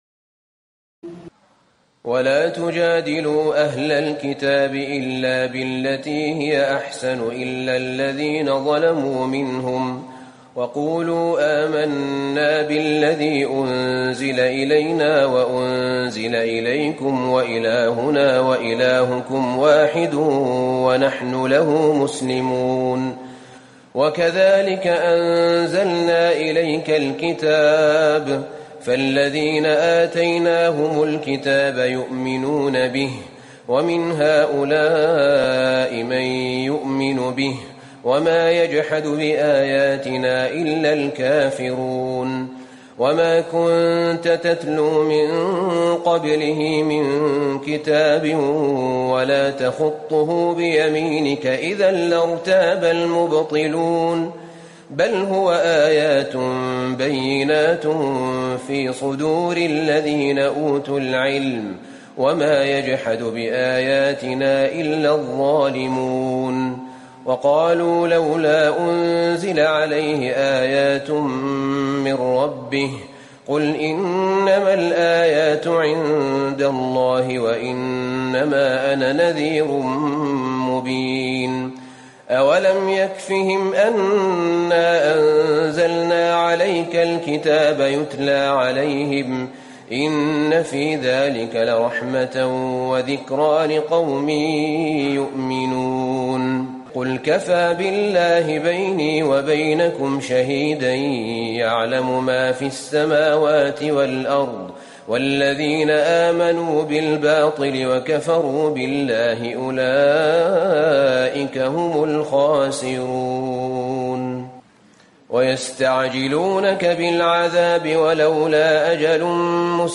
تراويح الليلة العشرون رمضان 1437هـ من سور العنكبوت (46-69) و الروم و لقمان (1-19) Taraweeh 20 st night Ramadan 1437H from Surah Al-Ankaboot and Ar-Room and Luqman > تراويح الحرم النبوي عام 1437 🕌 > التراويح - تلاوات الحرمين